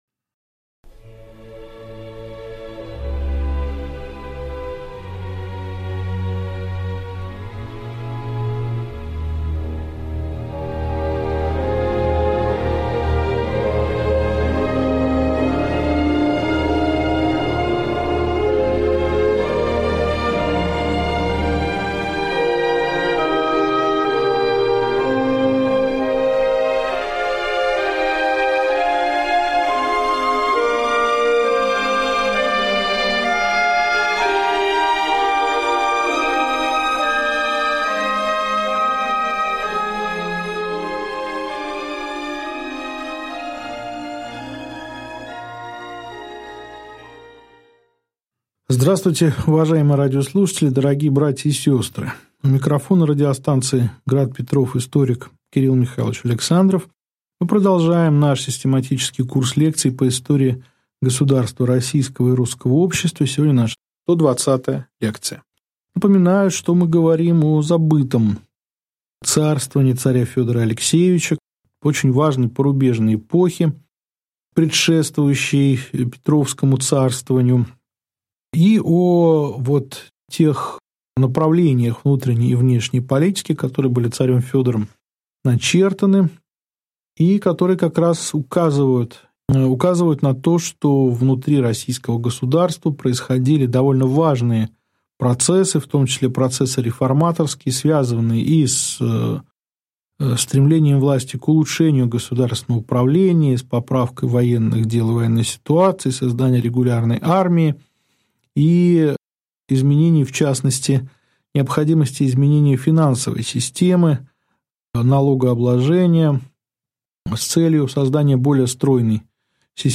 Аудиокнига Лекция 120. Другие реформы царя Федора Алексеевича | Библиотека аудиокниг